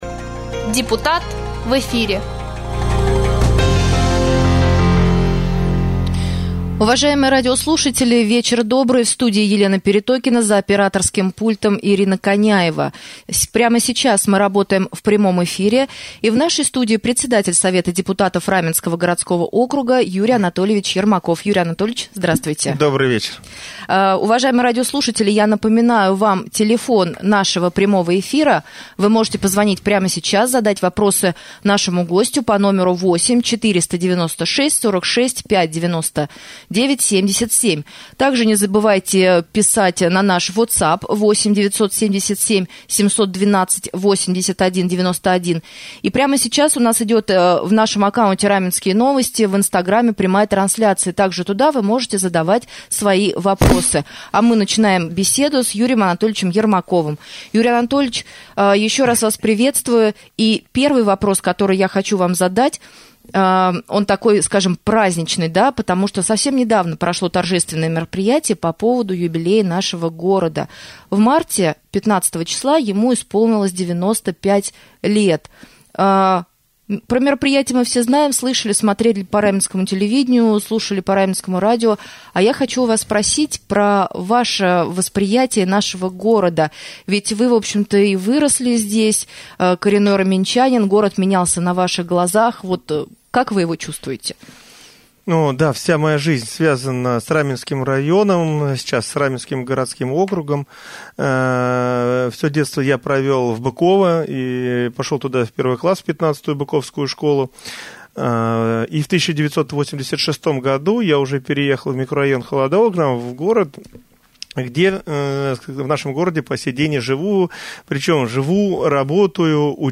Председатель Совета депутатов Раменского городского округа Юрий Анатольевич Ермаков стал гостем прямого эфира на Раменском радио 1 апреля.